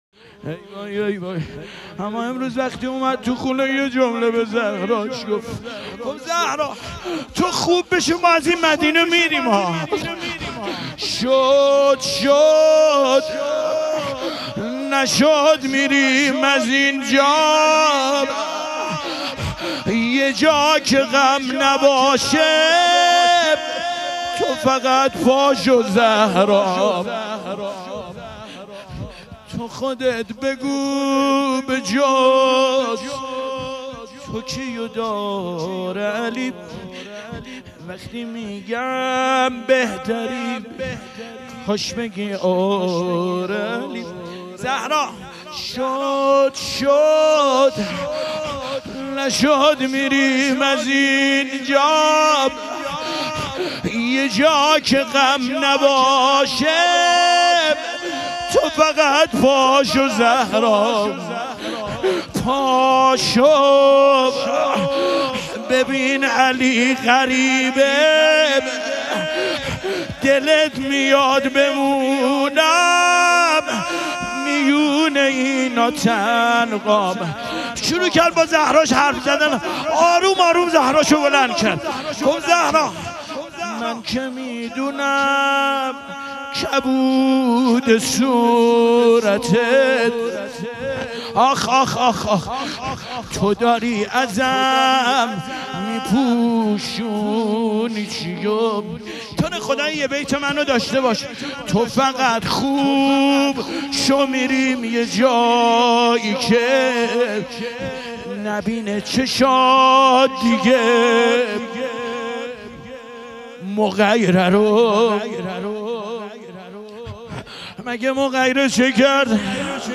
شام غریبان حضرت زهرا علیها سلام - روضه